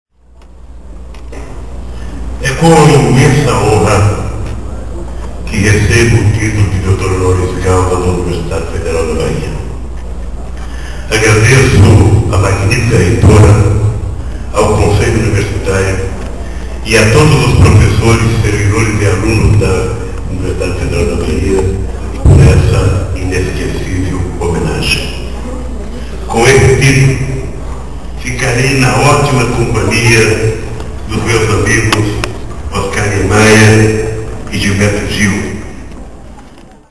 Ex-presidente Lula durante discurso
discurso do Doutor Honoris Causa da Ufba, Luiz Inácio Lula da Silva.